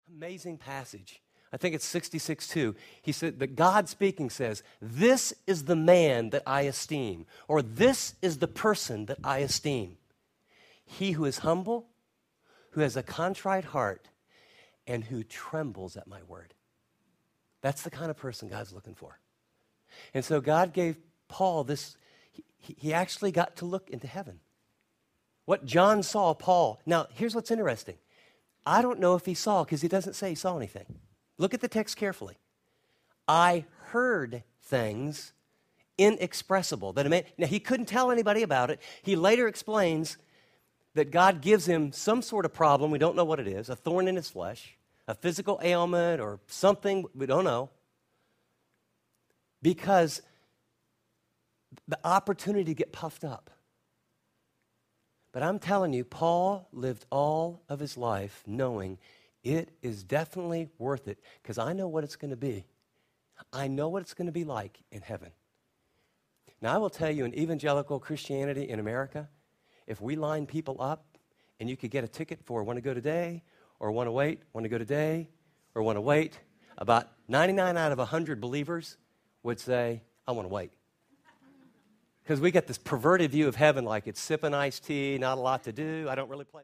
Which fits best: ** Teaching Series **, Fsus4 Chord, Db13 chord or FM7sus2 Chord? ** Teaching Series **